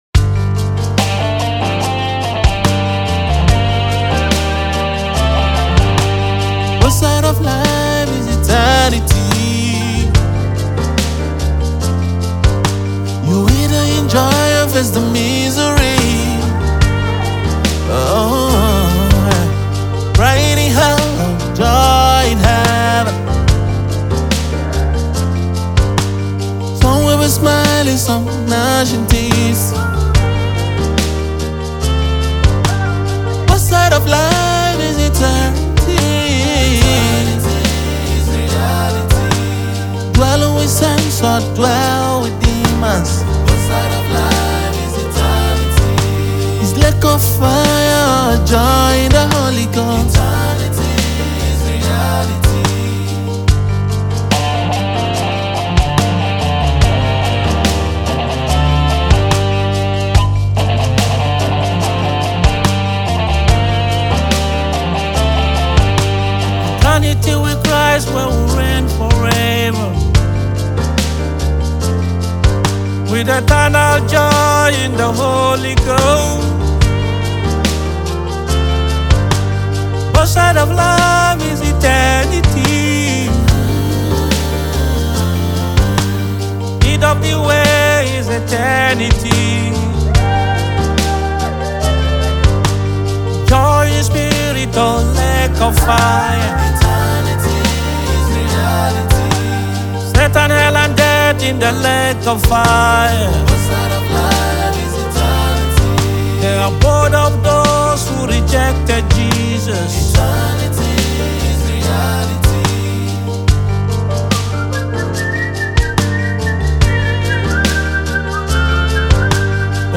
gospel artist
vocals are filled with conviction and passion